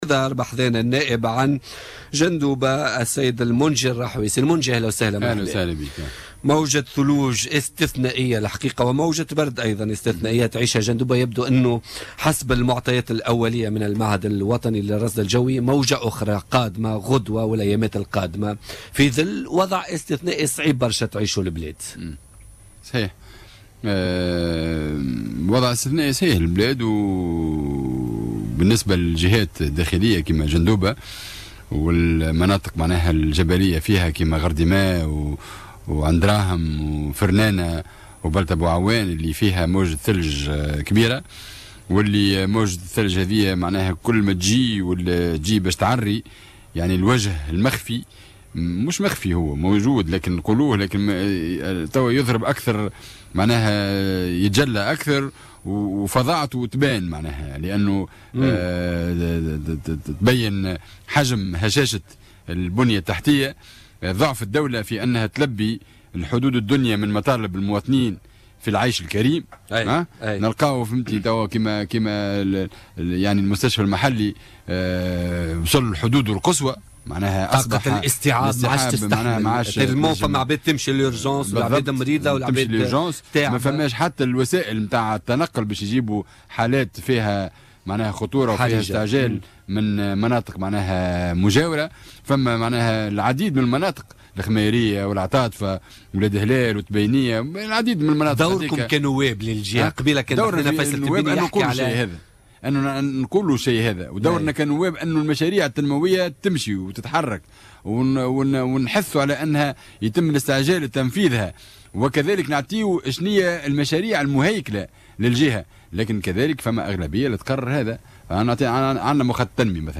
Le député du gouvernorat de Jendouba à l'ARP, Monji Rahoui, a déclaré lors de son passage dans l'émission Politica, ce mercredi 18 janvier 2017, que la situation dans les régions intérieures s'agrave de jour en jour.